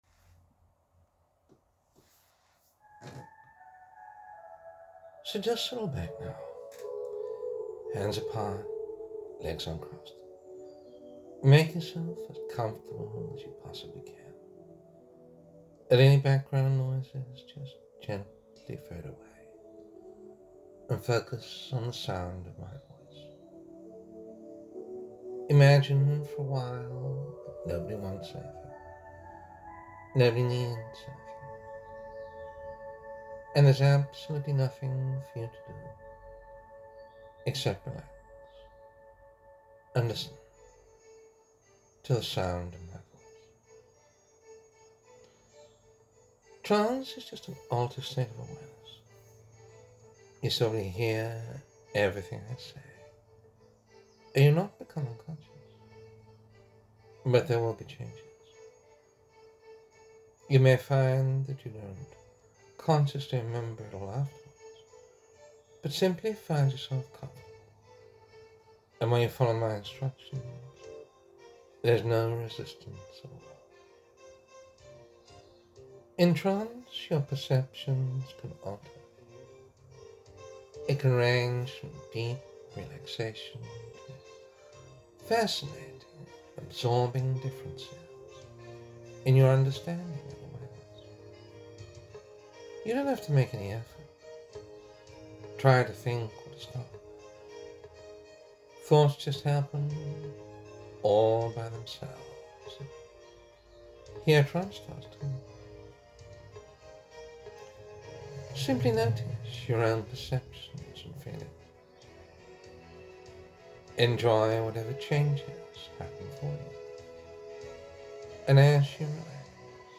A free guided meditation for those unfortunate enough to be suffering with cancer.
Cancer-Meditation.mp3